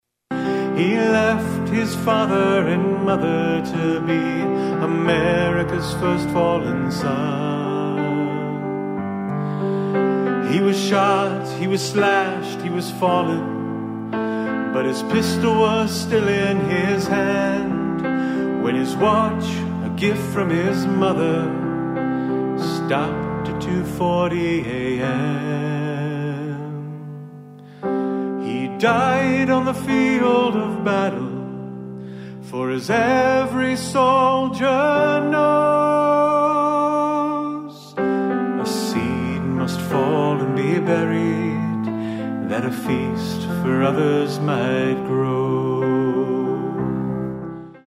Another previous Glidden resident has written and shared a ballad in honor of Merle D. Hay, the first Iowan, and the first American, killed in World War I, while fighting in the trenches near Artois, France.